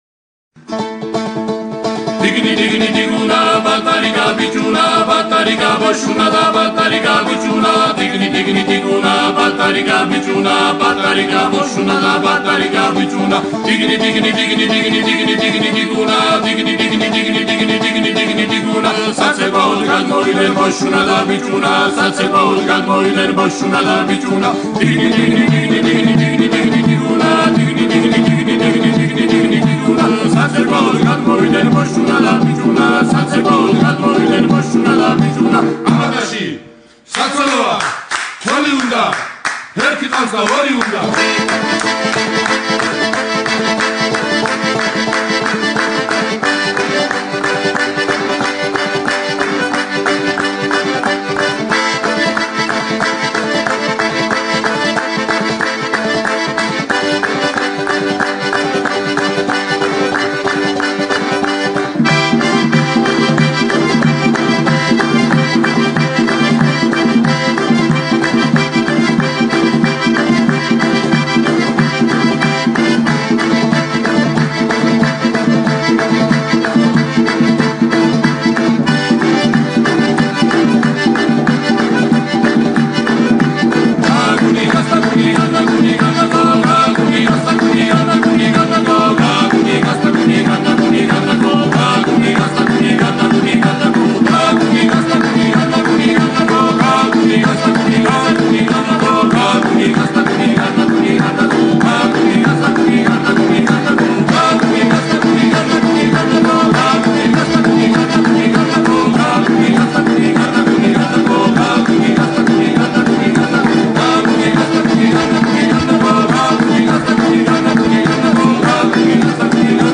Gürcüceden Türkçe’ye çevirebildiğim kadarıyla Yüzük Komedisi diye geçiyor ama rachuli yi ararken bunu buldum. Çok da iyi yapmışım özellikle 00:40 ile 01:24 arasındaki akordeon solosunu dinlemenizi ısrarla tavsiye ediyorum. Bildiğiniz insanı neşelendirip yüzünü güldürüyor yahu.